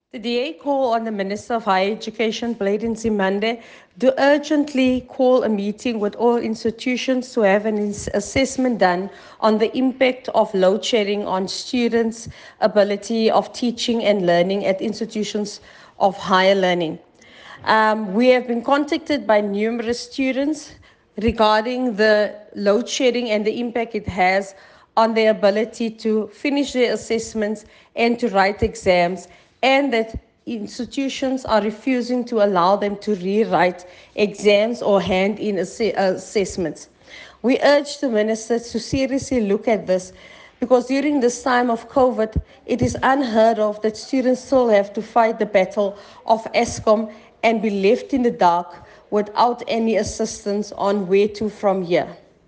soundbite by Chantel King MP.